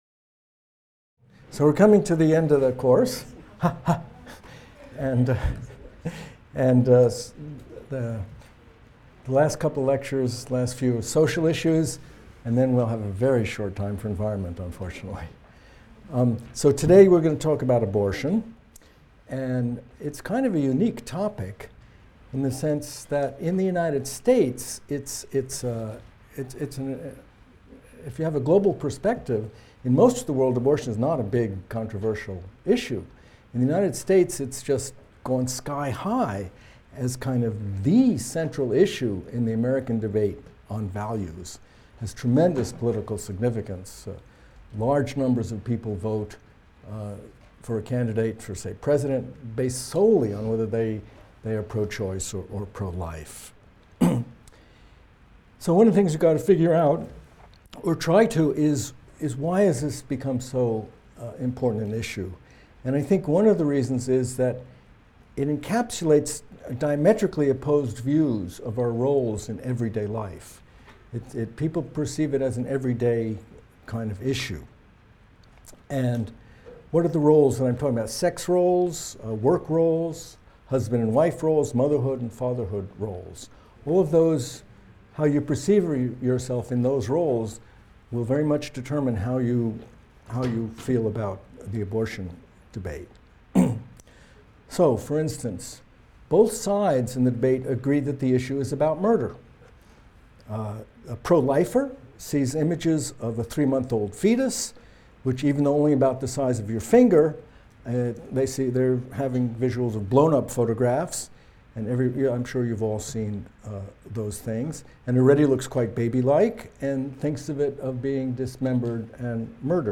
MCDB 150 - Lecture 21 - Global Demography of Abortion | Open Yale Courses